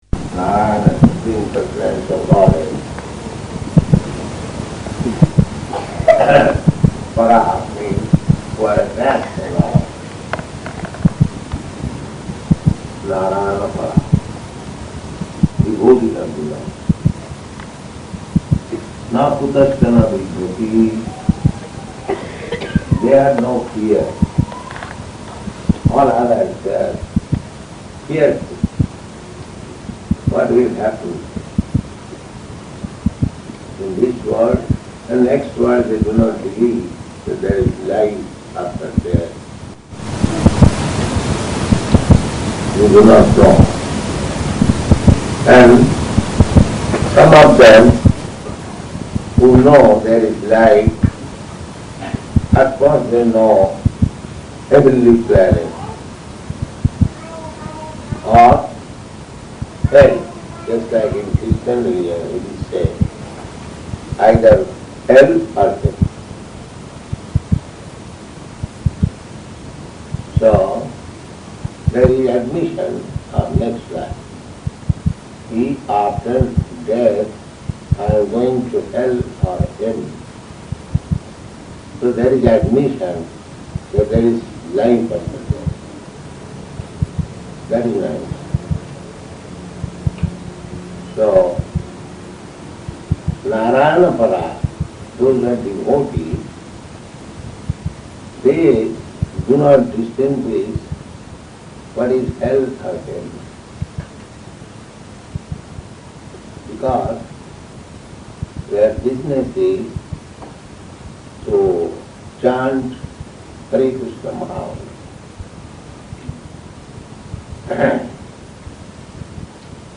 Lecture
Location: Pittsburgh